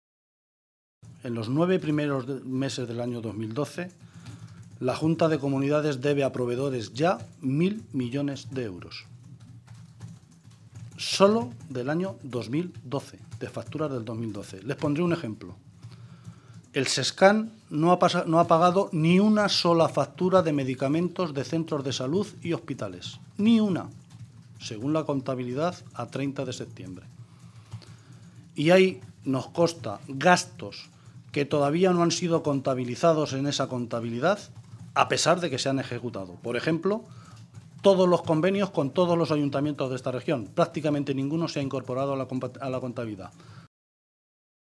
Guijarro realizó estas declaraciones en una rueda de prensa conjunta con los responsables sindicales de CC OO y UGT